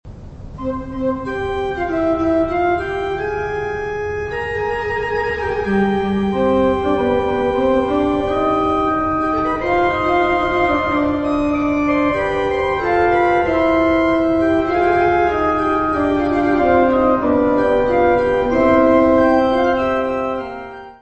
: stereo; 12 cm
Orgão histórico da Abbaye de Saint-Michel en Thiérache
orgão
Music Category/Genre:  Classical Music